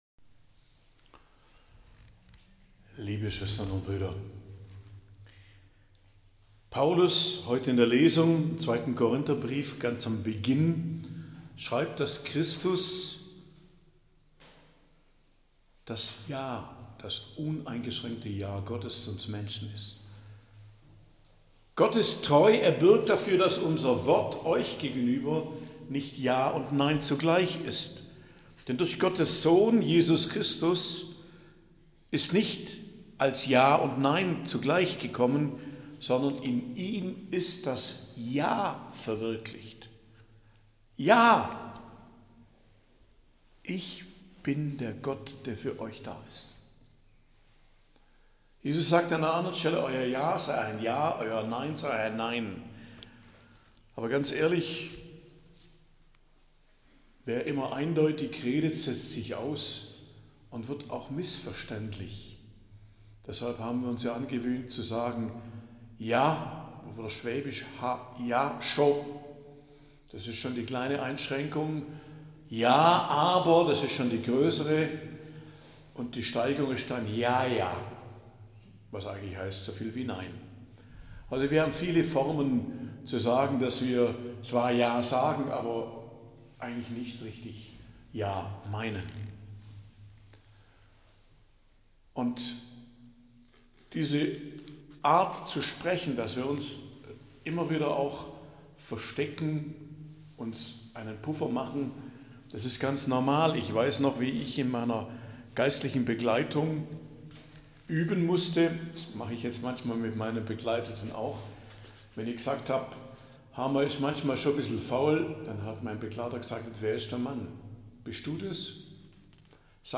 Predigt am Dienstag der 10. Woche i.J., 10.06.2025 ~ Geistliches Zentrum Kloster Heiligkreuztal Podcast